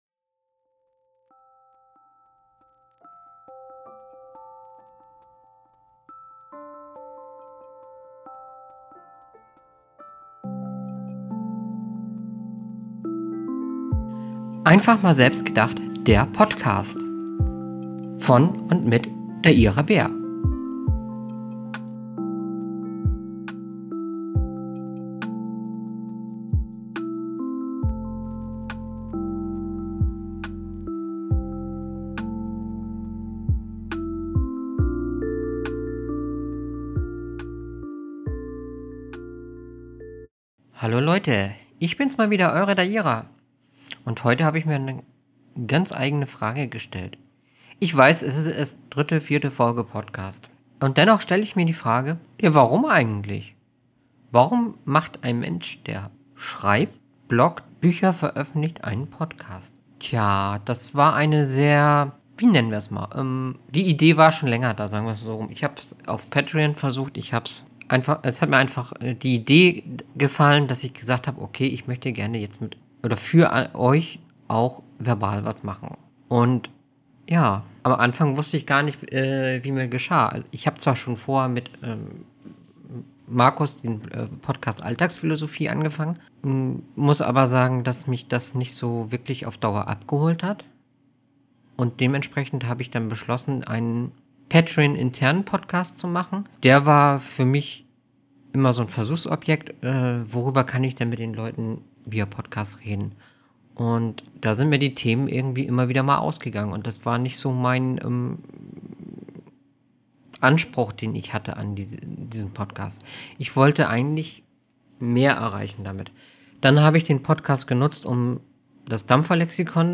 Hallo liebe Hörer, heute stelle ich mich der Frage, warum mache ich eigentlich auch noch einen Podcast Warum, versuche ich es immer wieder. Ausserdem gibt es noch eine kleine Leseprobe aus meinem Roman: Durch Dich Bin Ich Ein Besserer Mensch.